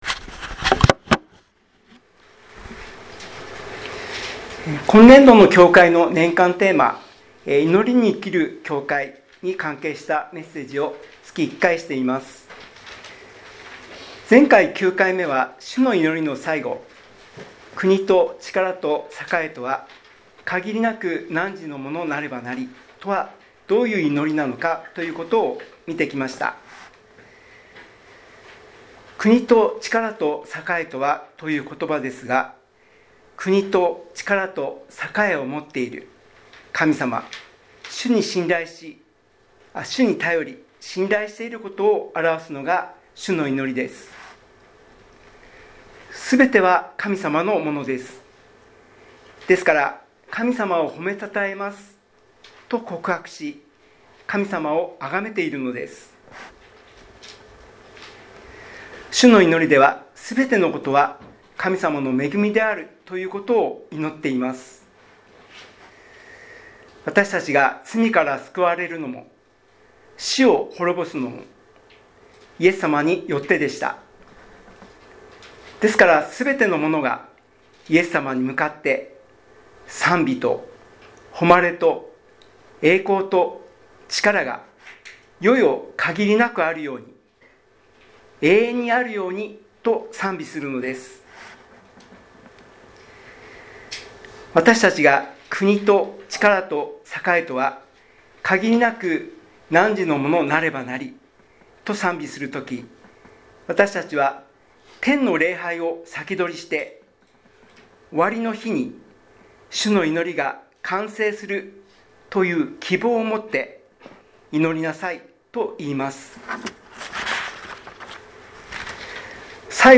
2026年1月4日聖日